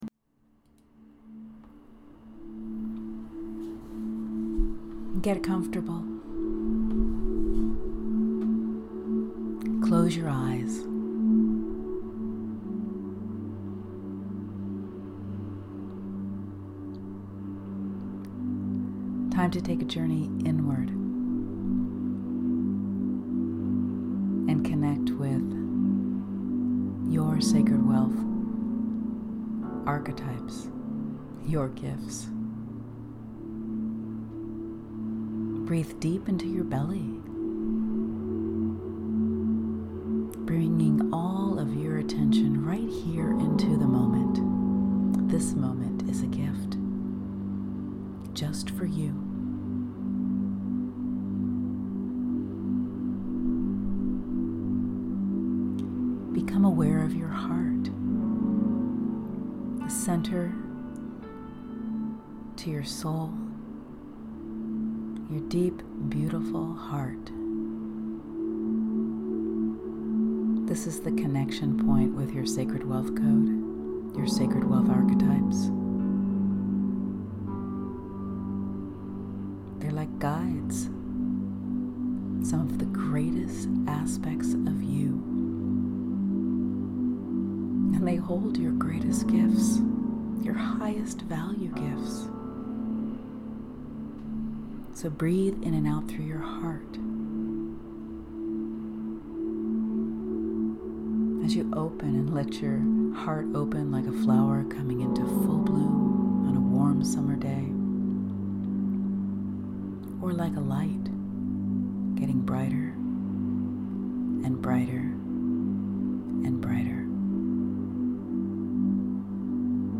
Sacred-Wealth-Code-Archetype-Meditation.mp3